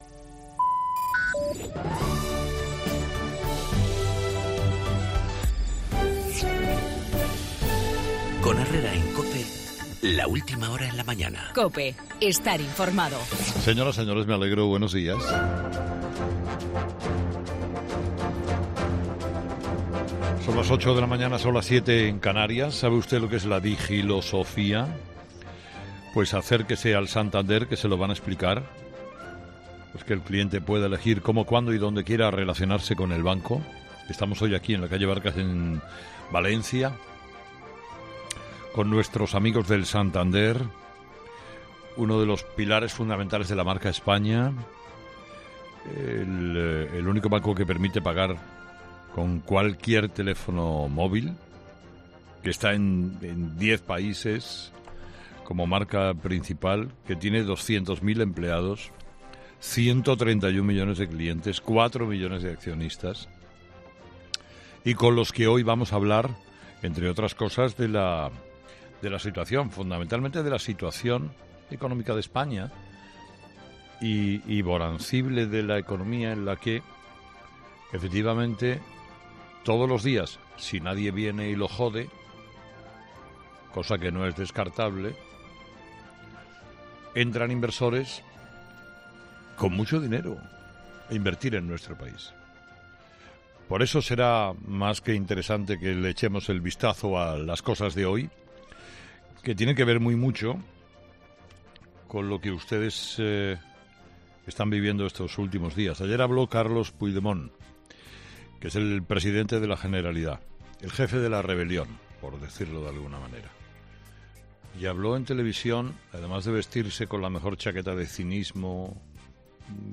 AUDIO: Carlos Herrera analiza desde el Banco Santander en Valencia los delitos a los que se enfrenta el presidente de la Generalidad
Monólogo de las 8 de Herrera